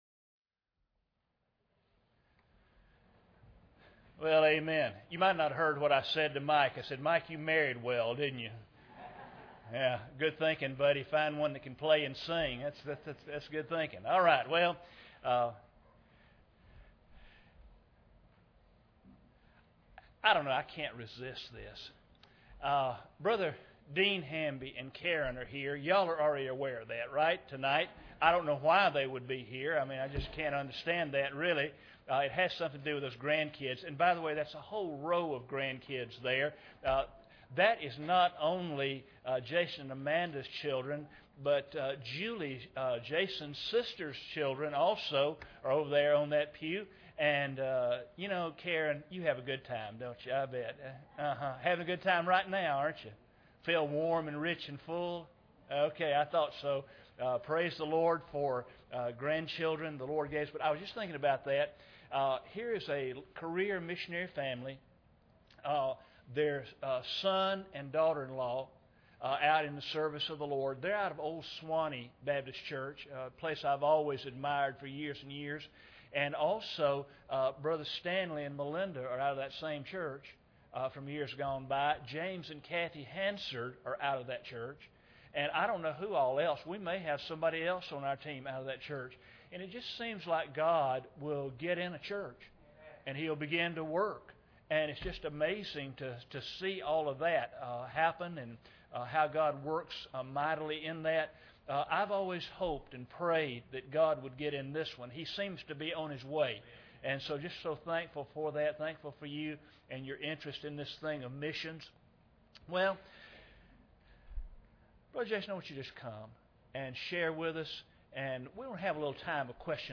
Series: 2015 Missions Conference Service Type: Sunday Evening